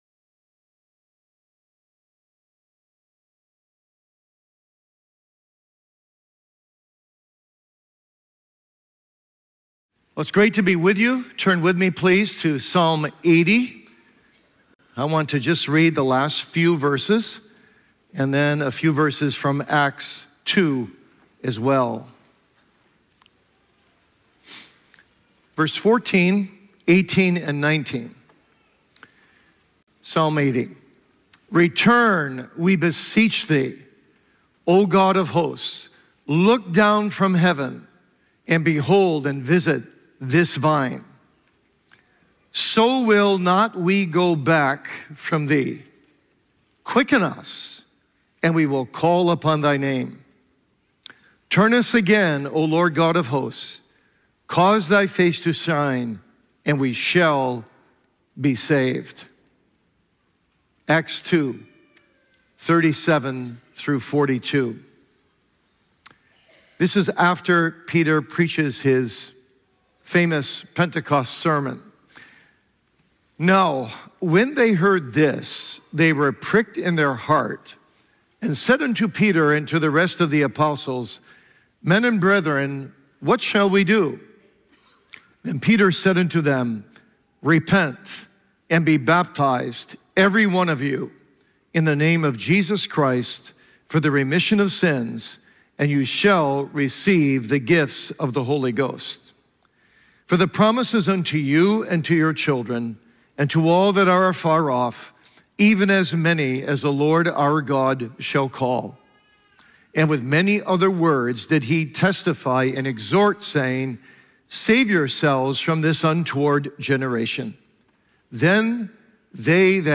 Sermons by Founders Ministries What Is Revival?